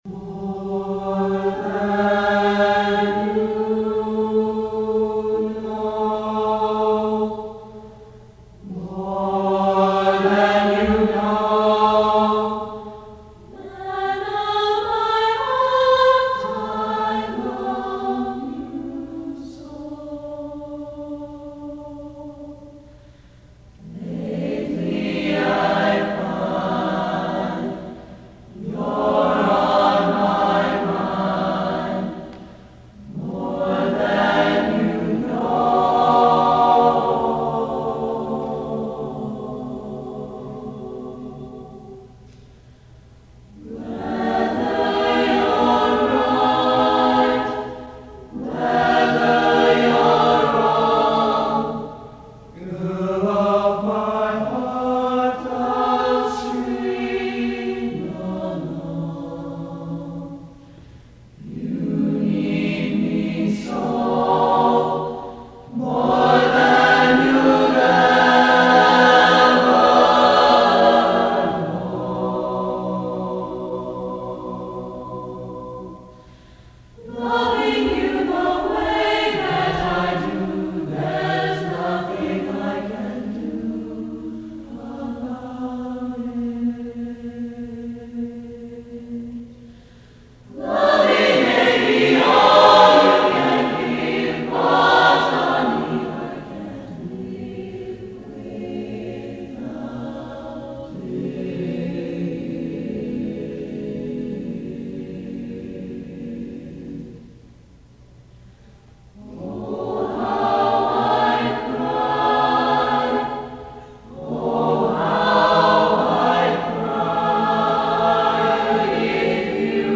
1994-1995 Choir Recordings